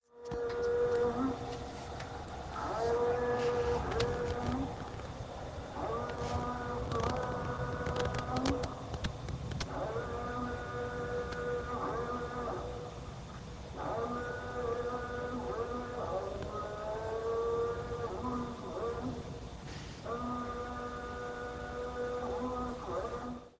Den Abend lassen wir in unserem Palmenhain ausklingen und lauschen den Klängen des Muezzins der aus Lautsprechern zum Gebet ruft.
muezzin.mp3